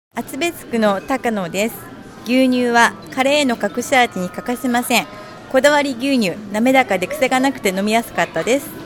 試飲いただいたお客様の生の声
リンクをクリックするとこだわり牛乳を試飲いただいた皆様からの感想を聞くことができます。
4月1日（火）15:00～18:00　スーパーアークス 菊水店
お客様の声4